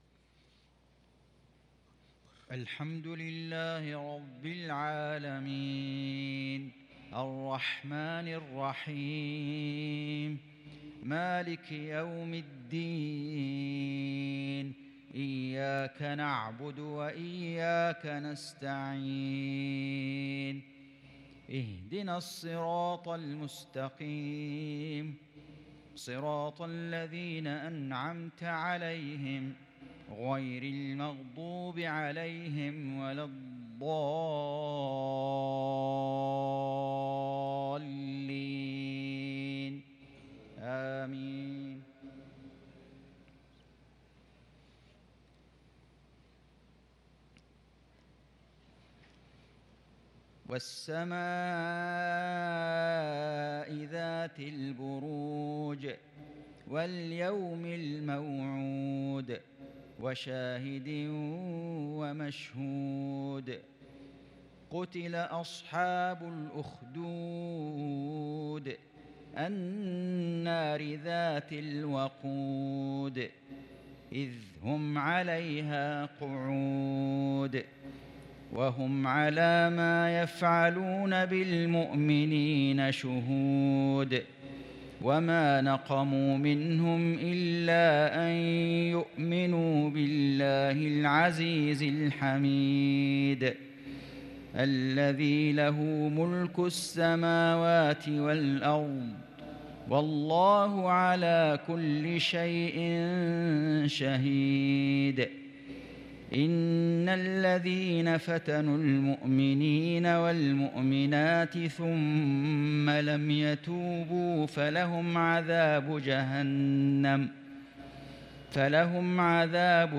عشاء الاحد ٥-٢-١٤٤٣هـ سورة البروج | Isha prayer from Surat Al-Al-Burooj 12/9/2021 > 1443 🕋 > الفروض - تلاوات الحرمين